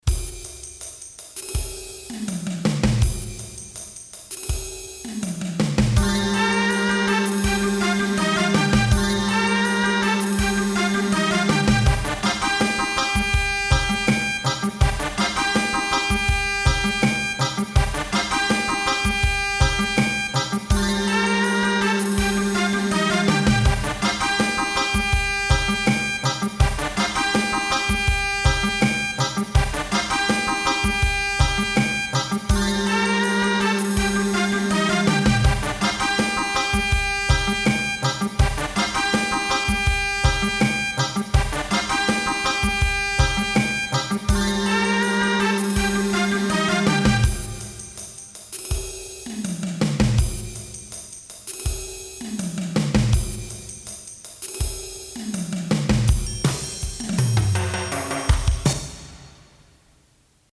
two-piece four-track project